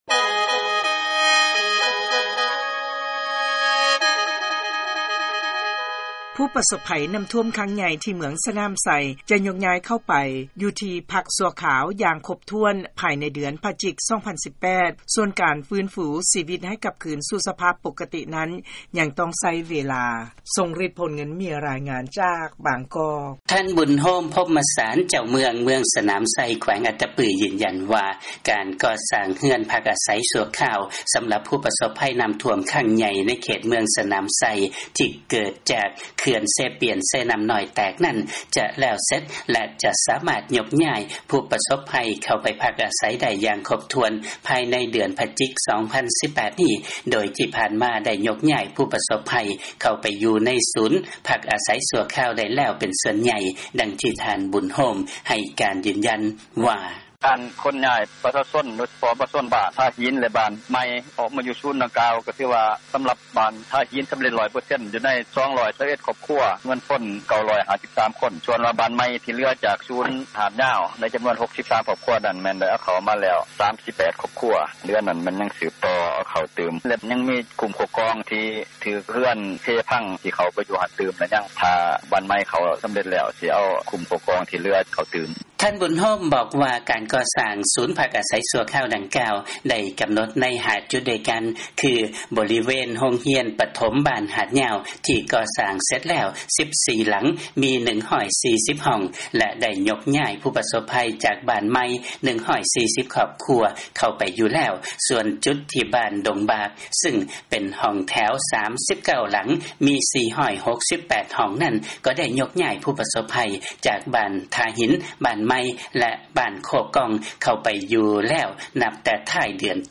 ເຊີນຟັງ ລາຍງານ ຜູ້ປະສົບໄພນໍ້າຖ້ວມ ຢູ່ເມືອງ ສະໜາມໄຊ ຈະຍ້າຍເຂົ້າໄປຢູ່ ທີ່ພັກຊົ່ວຄາວ ພາຍໃນ ເດືອນພະຈິກ 2018